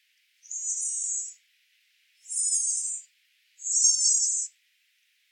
La presencia de estos sonidos puede indicar en algunas especies animales dominancia, apareamiento o cortejo; llamados de alerta al peligro; reconocimiento entre madres y crías etc. Este repositorio digital contiene grabaciones de fauna silvestre residente en la península de Baja California, resultado del proyecto de investigación en el área natural protegida Sierra de la Laguna.
Polioptila_californica.mp3